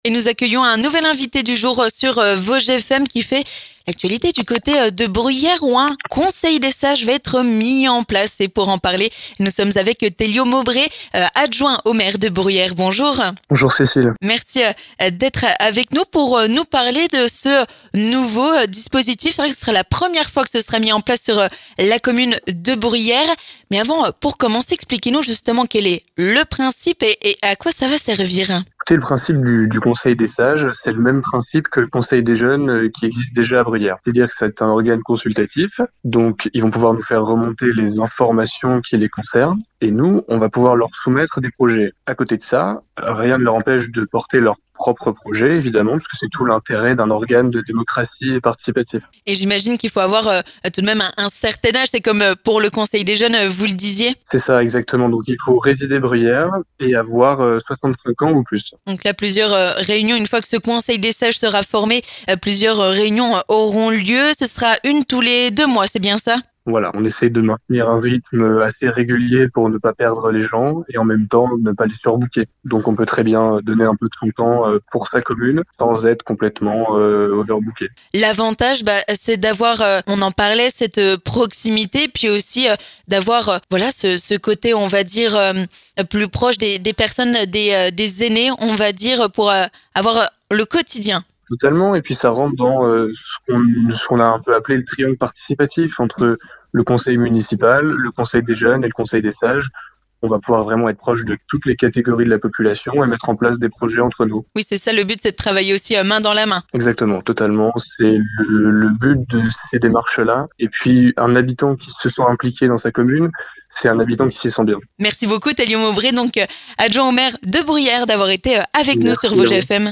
L'invité du jour
On en parle avec notre Invité du Jour sur Vosges FM, l'adjoint au maire de Bruyères, Télio Maubré.